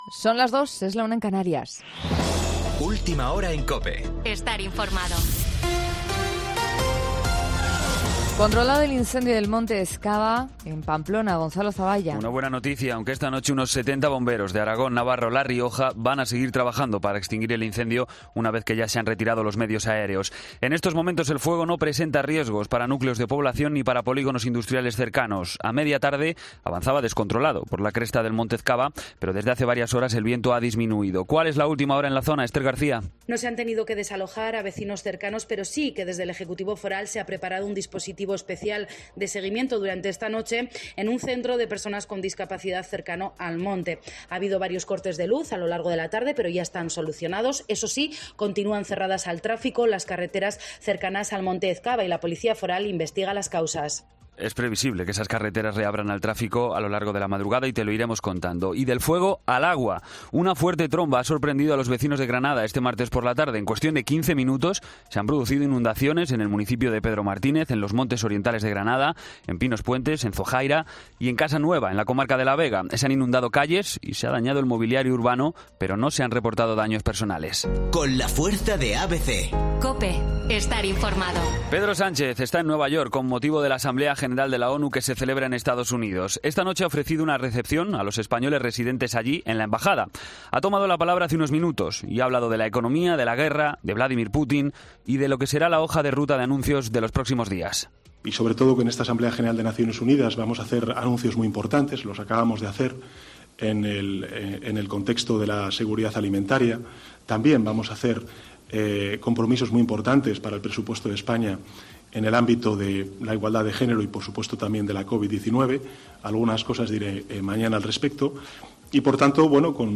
Boletín de noticias COPE del 21 de septiembre a las 02:00 hora
AUDIO: Actualización de noticias Herrera en COPE